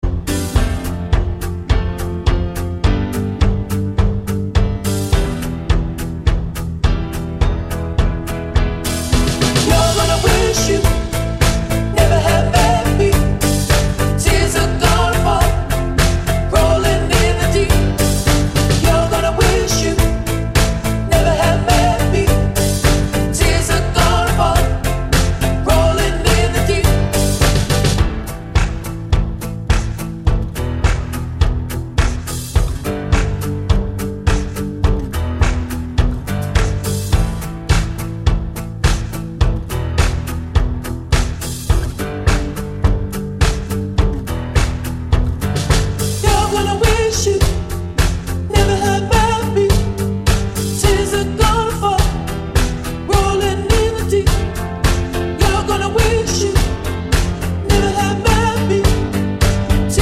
Down 3 Semitones Pop (2010s) 3:48 Buy £1.50